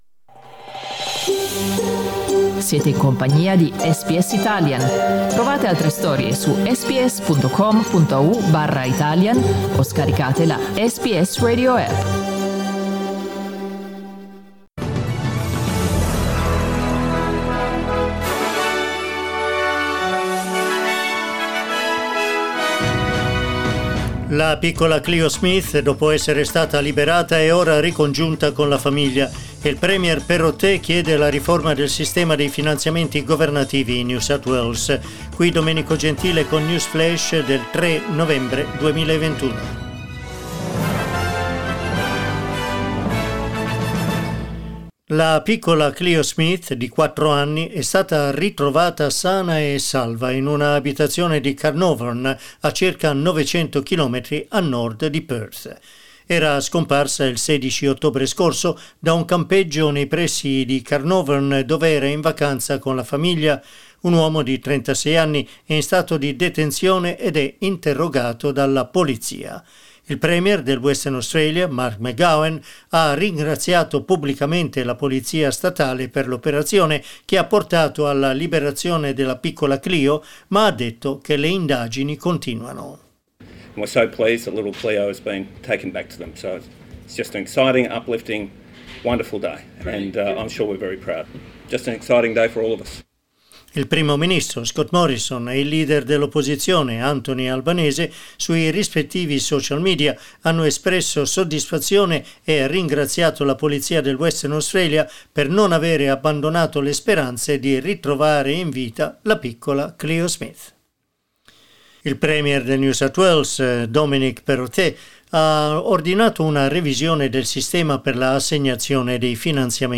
News flash mercoledì 3 novembre 2021
L'aggiornamento delle notizie di SBS Italian.